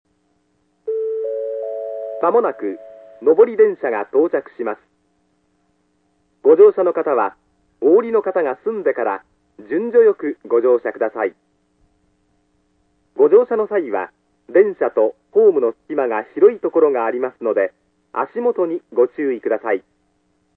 ●スピーカー：ソノコラム・小
●音質：D
１番線 接近放送・下り（大牟田方面）　(100KB/20秒）
男性声のみの行先を言わない簡易放送であり、「上り」「下り」の２パターンあります。